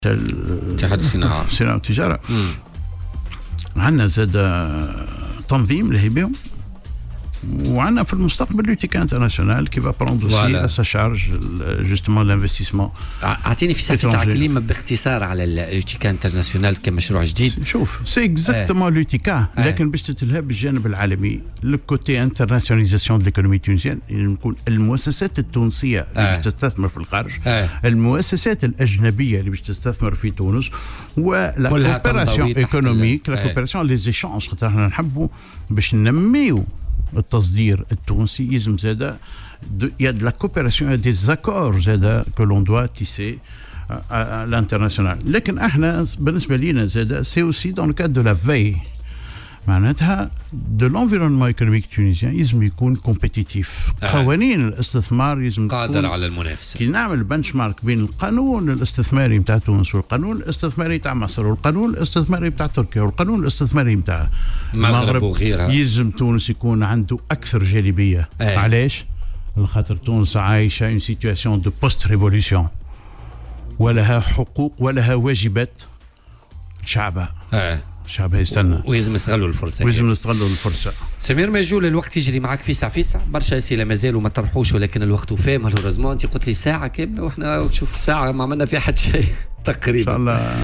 وأوضاف ضيف "بوليتيكا" على "الجوهرة أف أم" أن المنظمة ستهتم بالجانب العالمي والمؤسسات الأجنبية المستثمرة في تونس وبالتعاون الدولي.